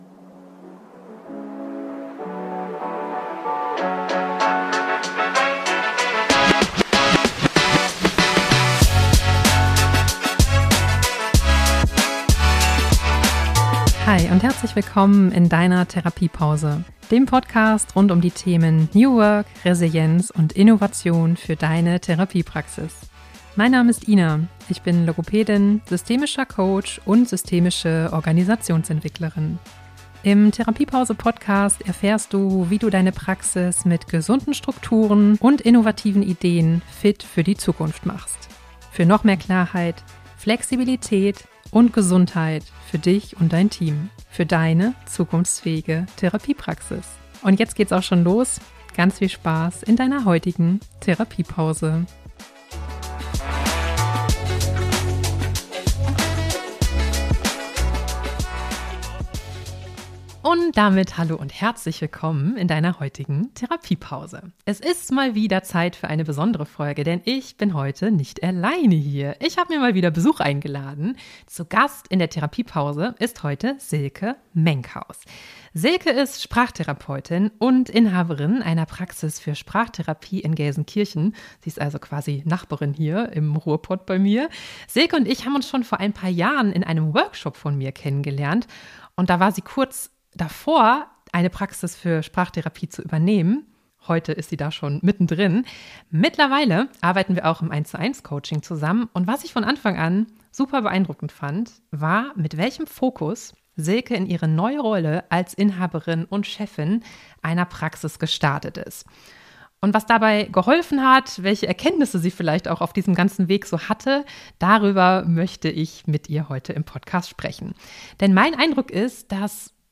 Ein ehrliches Gespräch über Neuanfänge, Authentizität und den Mut, sich auch mit viel Berufserfahrung nochmal komplett neu zu erfinden.